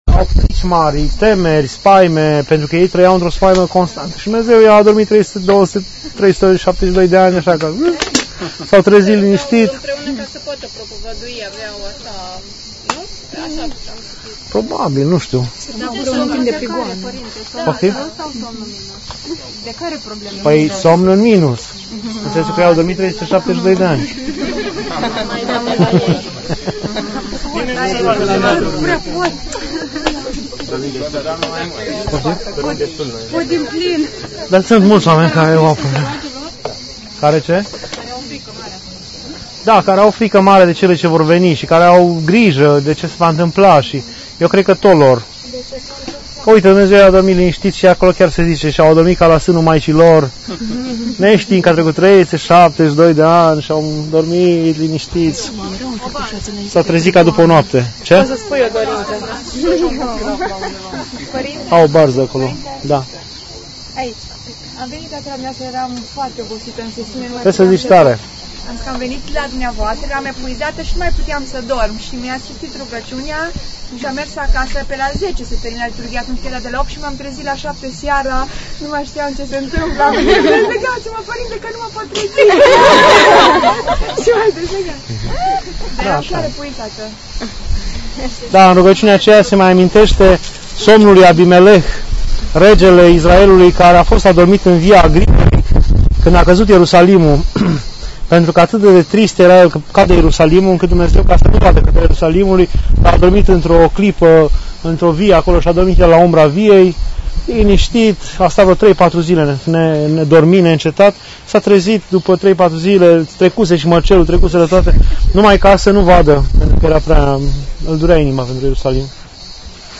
Pelerinaj 2 Turcia, Grecia